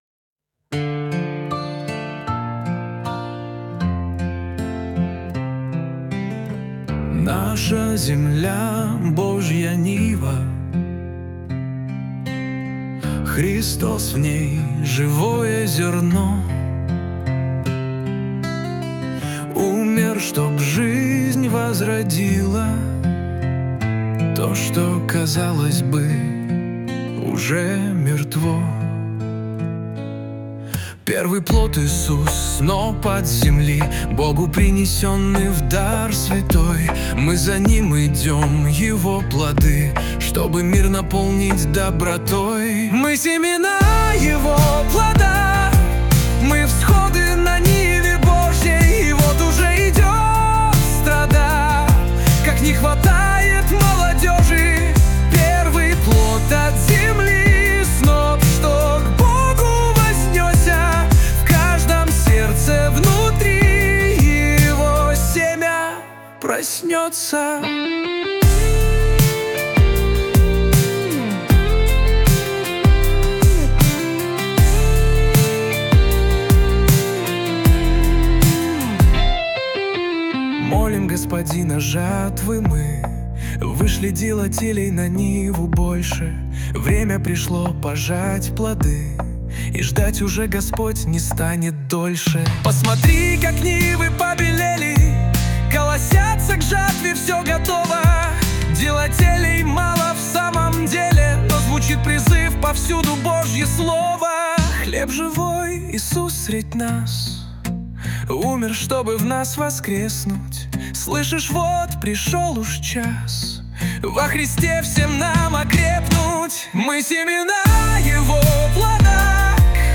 песня ai
324 просмотра 1441 прослушиваний 74 скачивания BPM: 78